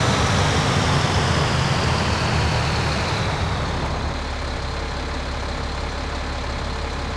throttle_off.wav